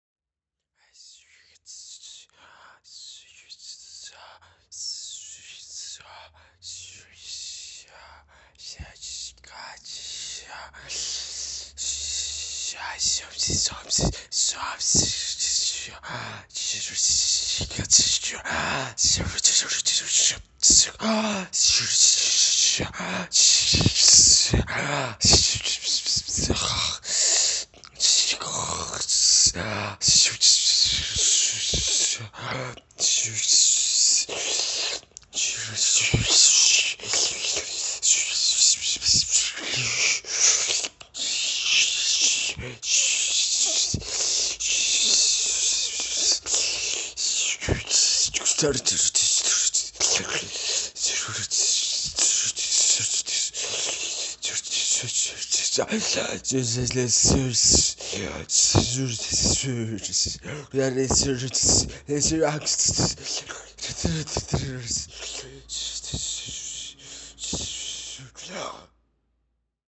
Susurro | SÓNEC | Sonoteca de Música Experimental y Arte Sonoro
Nota de contenido: Acción de susurrar
Susurro.mp3